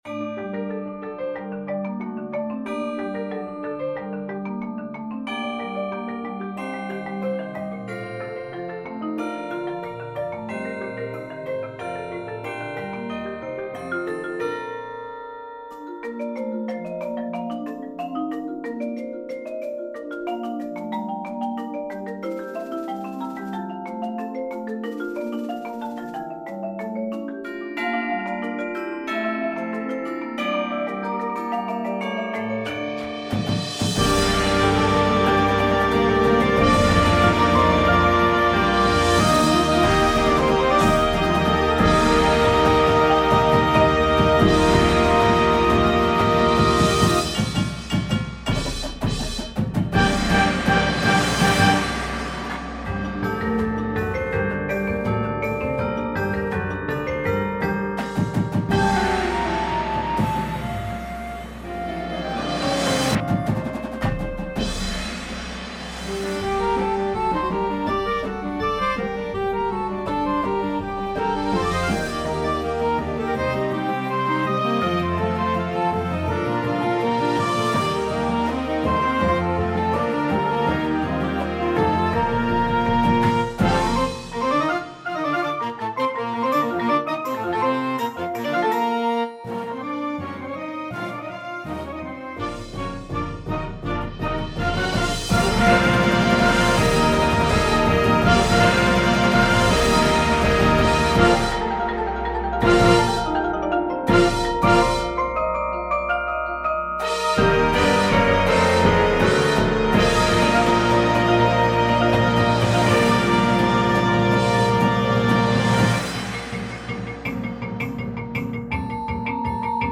• Flute
• Clarinet 1, 2
• Alto Sax 1, 2
• Trumpet 1
• Horn in F
• Trombone 1, 2
• Tuba
• Snare Drum
• Tenors
• Bass Drums
• Front Ensemble